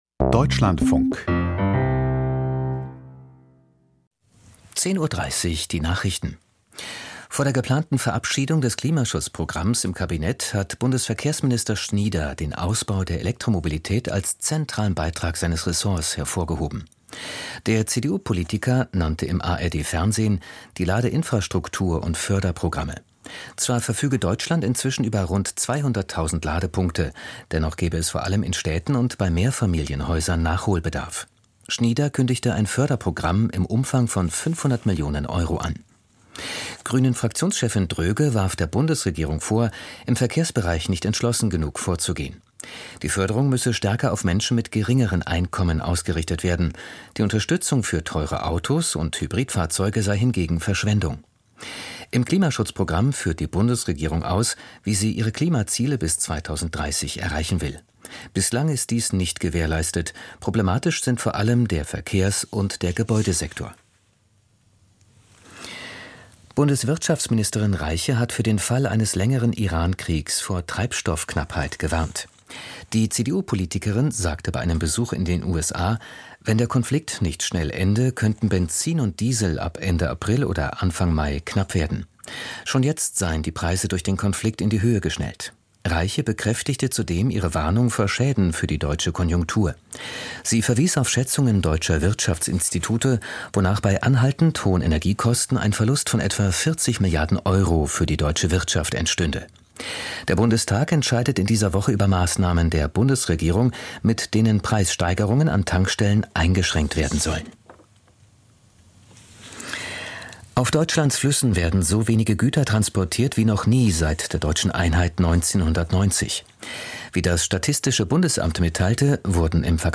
Die Nachrichten vom 25.03.2026, 10:30 Uhr